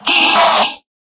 mob / endermen / scream1.ogg
scream1.ogg